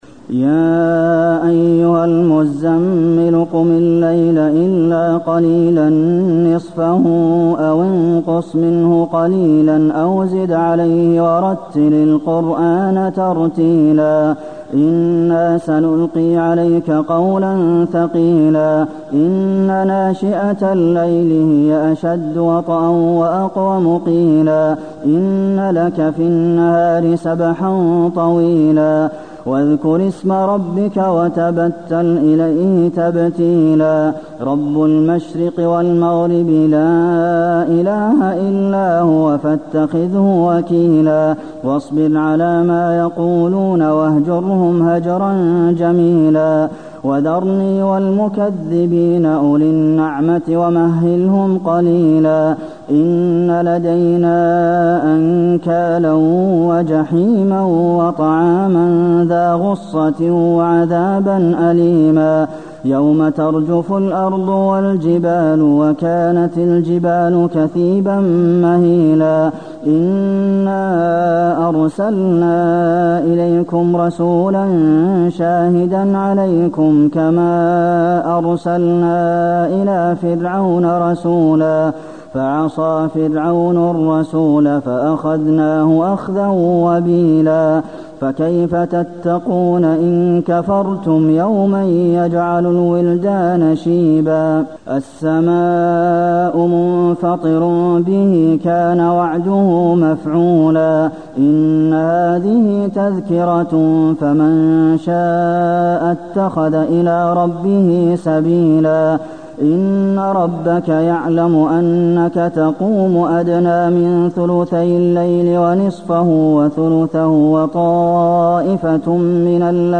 المكان: المسجد النبوي المزمل